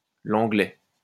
Langley (French pronunciation: [lɑ̃ɡlɛ]